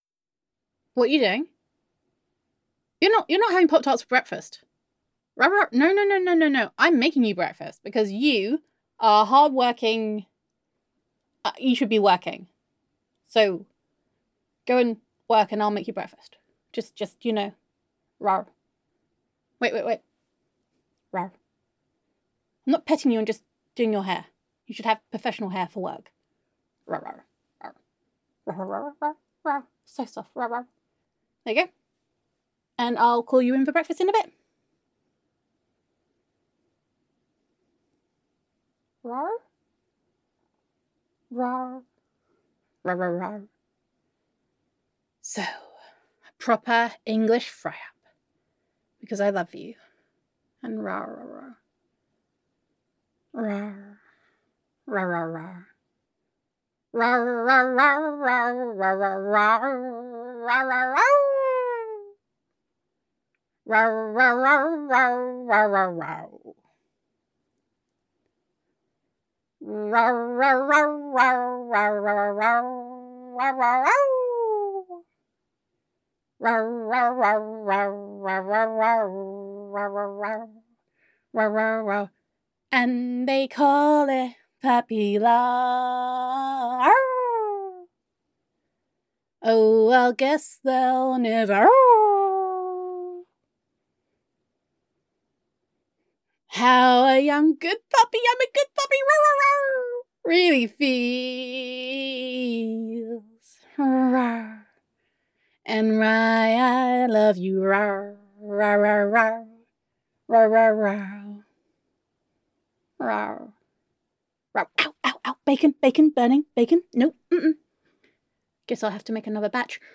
[F4A] Whining and Dining [Singing][Puppy Love][Happy Werewolf Sounds][Tail Stain][Petting You][Pet Names][Bacon][Bacon][Bacon][Gender Neutral][Your Tsundere Werewolf Roommate Makes You Breakfast]